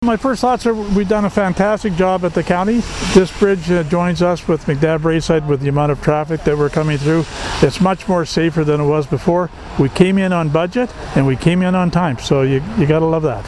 Wednesday afternoon’s ceremony drew dignitaries from both Renfrew County and the neighbouring communities of McNab-Braeside and Arnprior to site on Madawaska Boulevard.
Arnprior County Councillor Dan Lynch observed this is the initial major infrastructure completed under his first-term watch.
dec-9-dan-lynch-bridge-opening-2.mp3